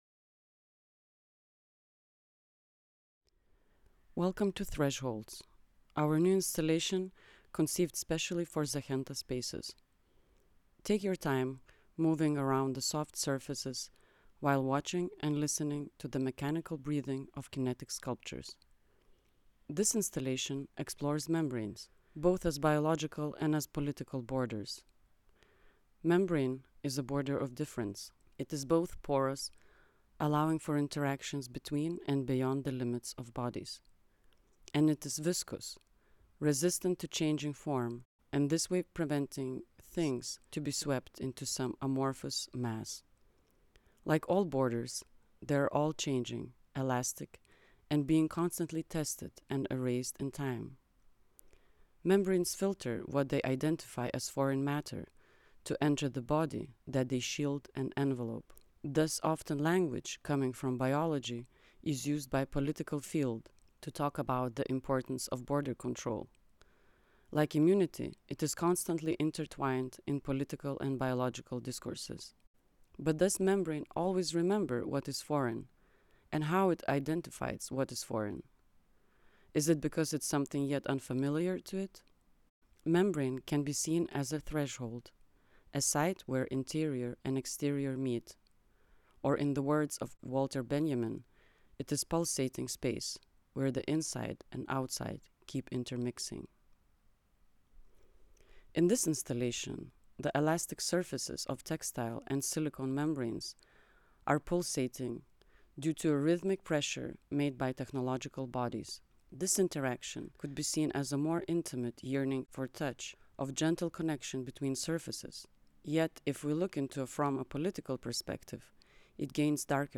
Audioguide for the exhibition Pakui Hardware. Progi / Thresholds